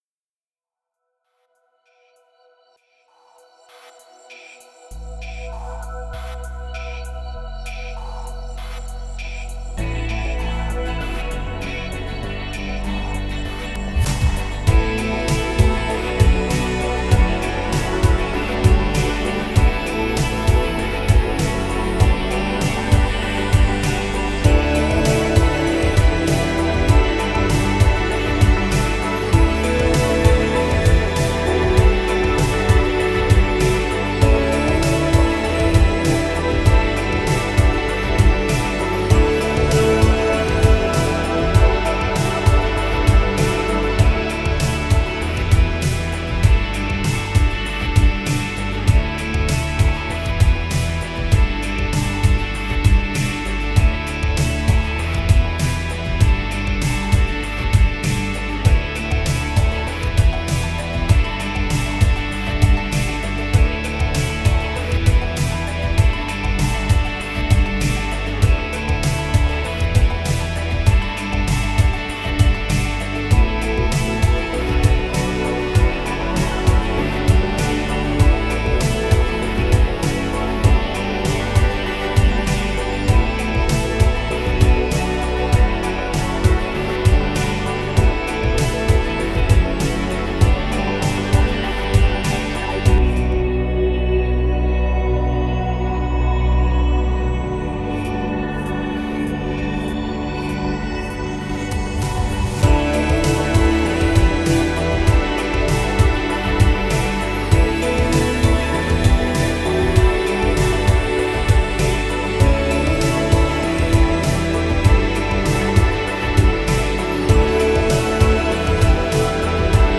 This is a soothing 80's dream pop tune featuring our Evolution Strawberry electric guitar library. The stereotypical echoey drums are embellished with some fantastic subtle synth effects, making it an easy listen without being overly simplistic. There's also a ton of gorgeous ambient guitars in the background, layered together to form a cushiony support for the more prominent instruments.